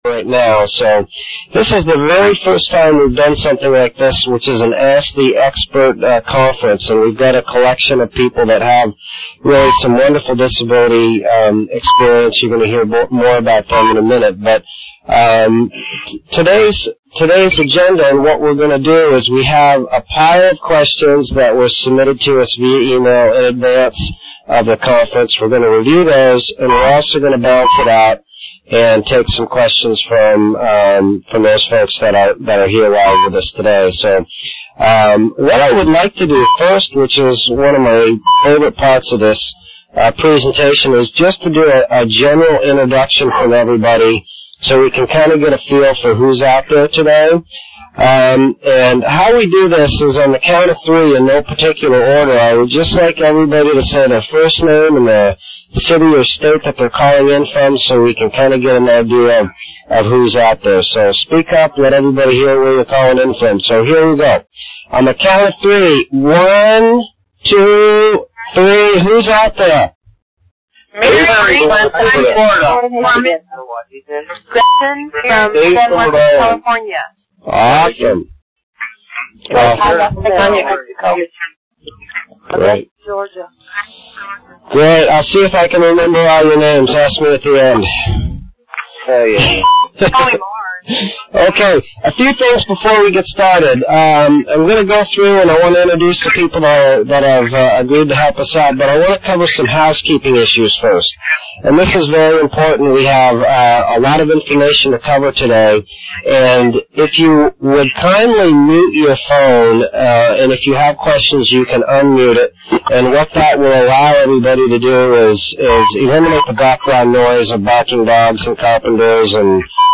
The replay and MP3, for the March 5 2008 ASK THE EXPERT Disability Conference is below, you’ll learn from 5 disability experts and over 250 attendee’s,